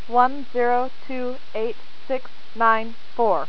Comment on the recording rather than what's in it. You will hear a list of seven numbers When the file is done, press the start button to start the timer.